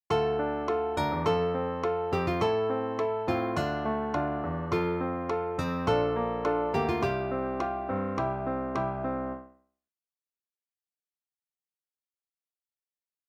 So far, assuming I take the first chord progression above (Dm  F  Am  Dm) as my opening progression, the whole tune so far sounds like this: [